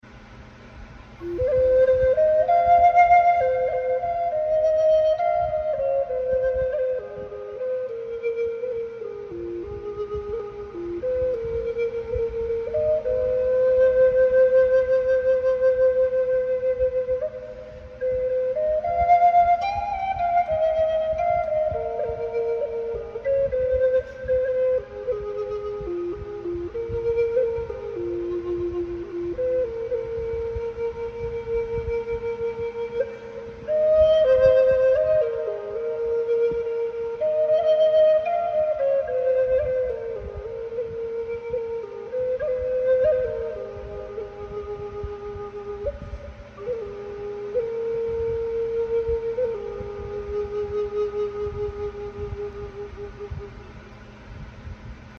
New Flute In Process …